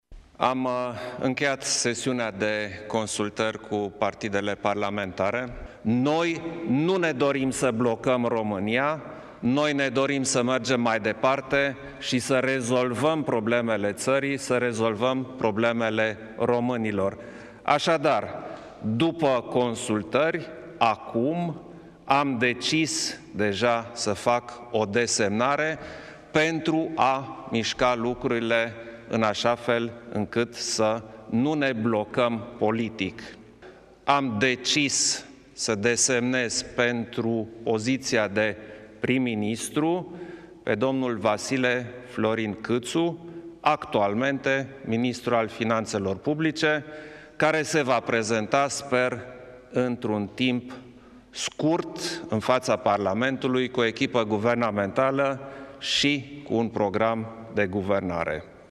Anunțul a fost făcut în urmă cu câteva minute de șeful statului, după consultările avute în a această după amiază cu reprezentanții partidelor parlamentare.